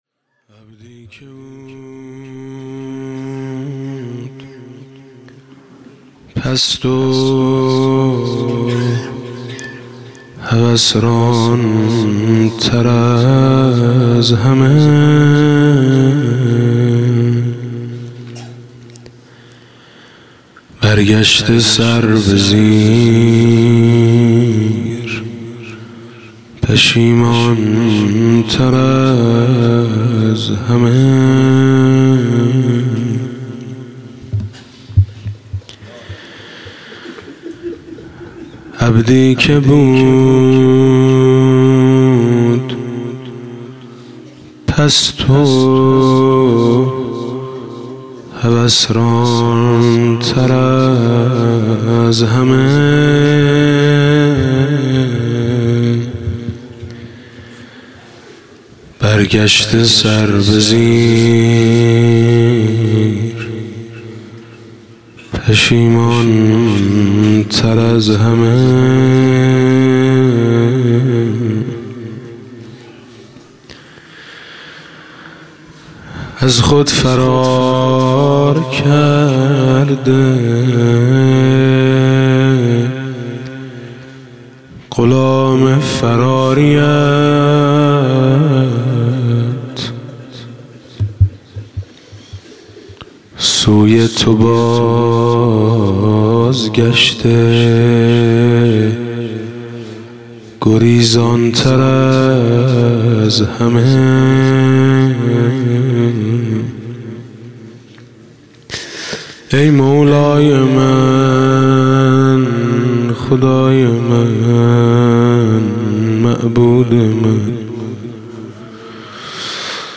3 خرداد 97 - هیئت میثاق با شهدا - مناجات - از خود فرار کرده غلام فراری ات